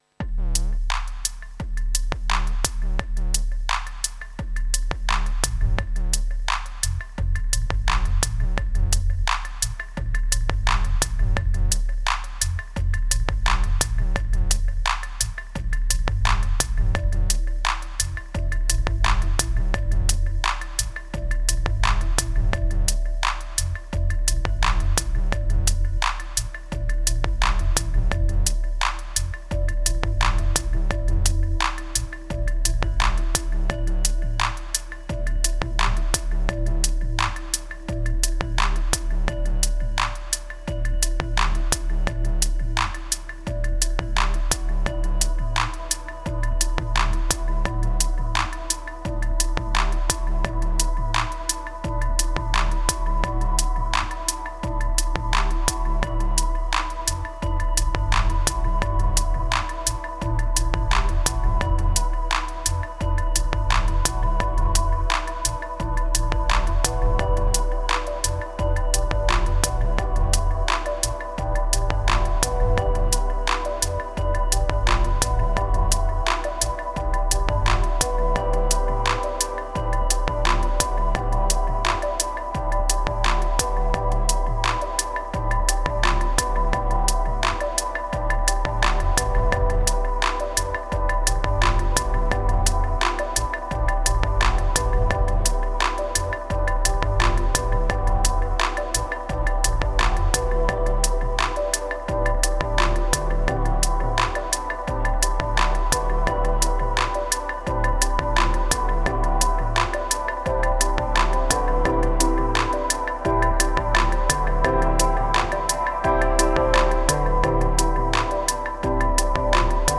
604📈 - 93%🤔 - 86BPM🔊 - 2021-11-04📅 - 1404🌟